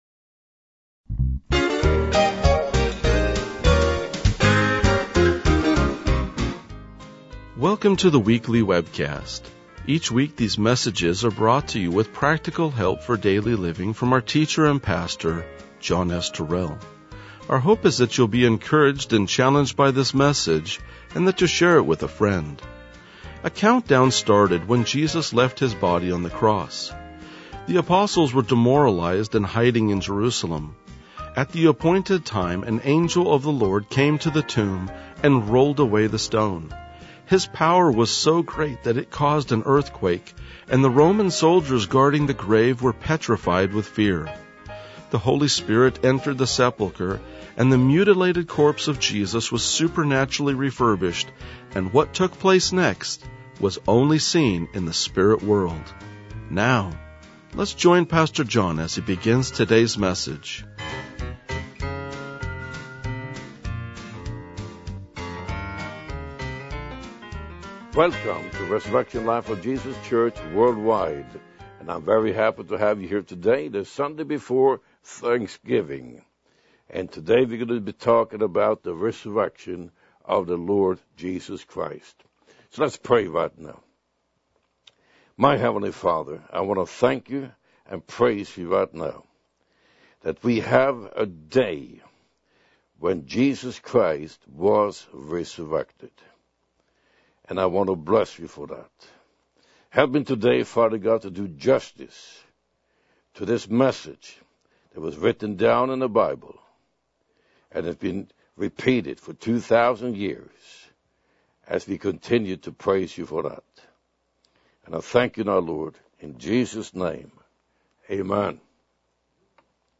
RLJ-1990-Sermon.mp3